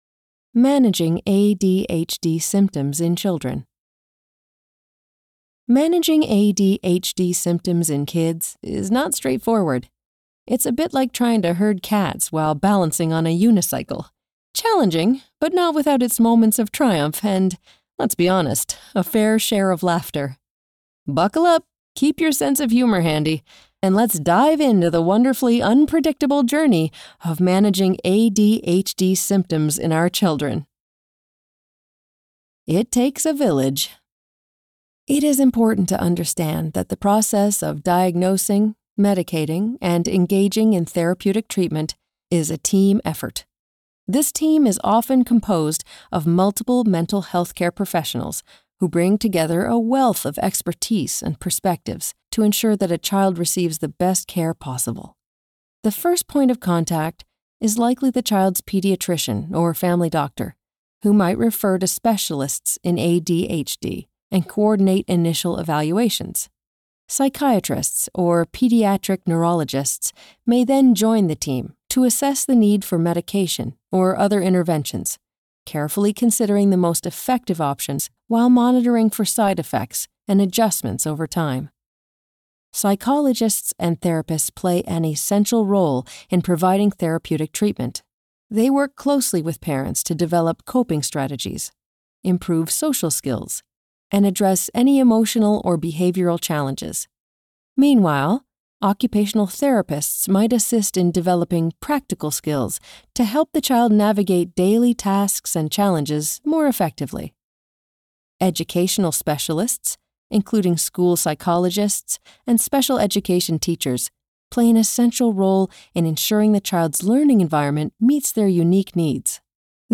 Warm, flexible, seasoned, and authentic.
Audiobook Nonfiction (US General)